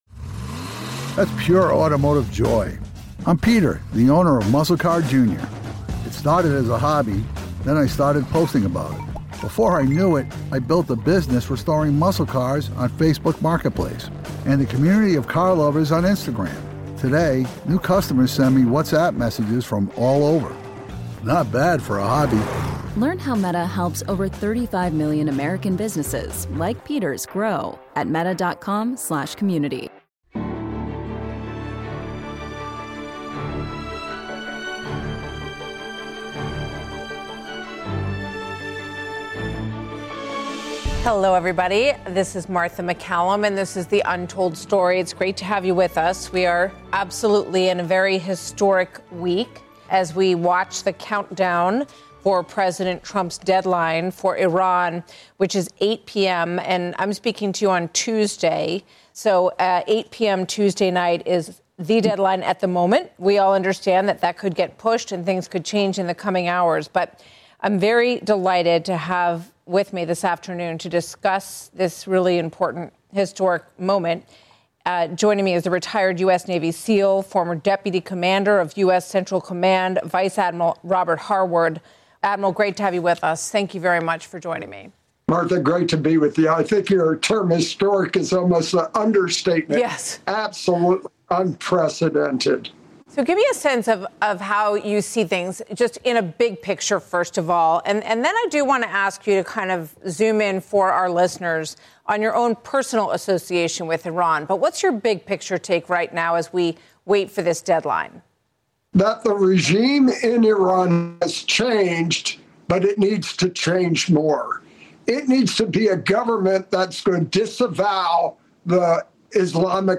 JINSA Iran Policy Project Advisor VADM (ret.) Robert Harward joined Fox News Radio’s Martha MacCallum on The Untold Story to highlight the importance of President Trump’s decision to act against the Iranian regime’s decades of maligned activities.